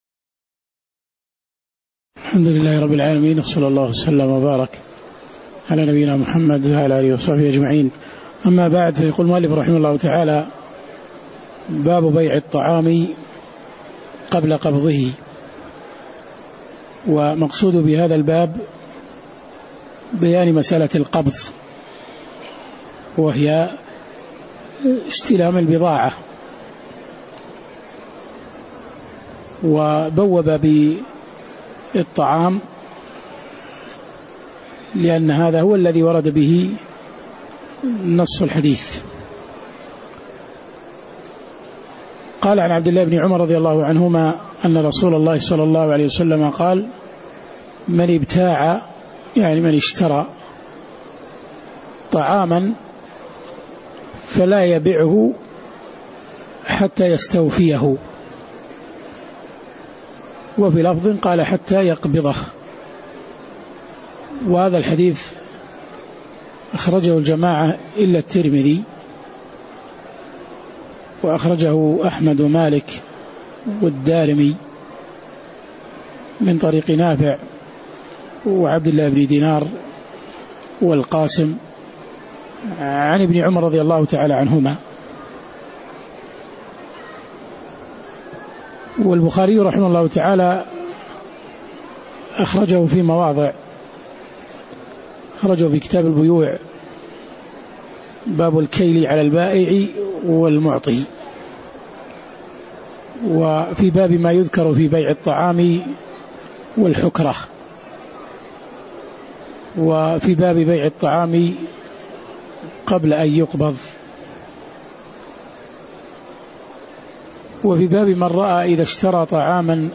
تاريخ النشر ١٨ محرم ١٤٣٩ هـ المكان: المسجد النبوي الشيخ